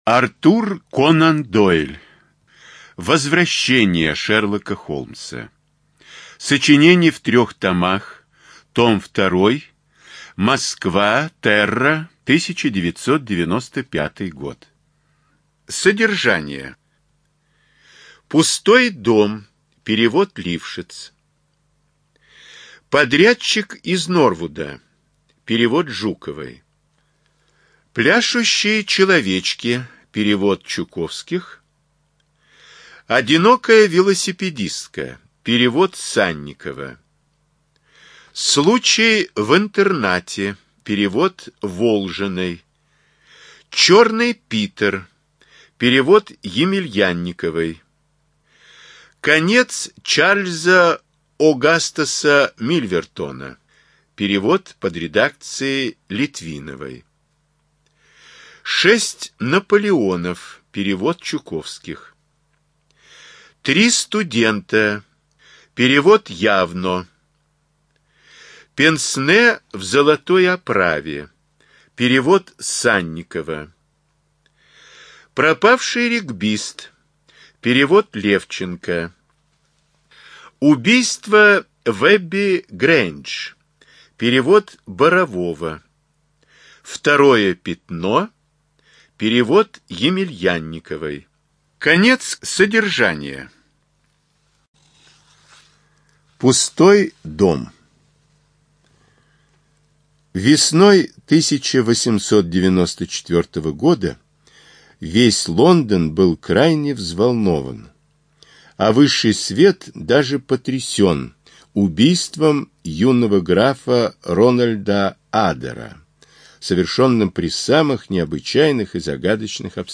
ЖанрДетективы и триллеры, Классическая проза
Студия звукозаписиЛогосвос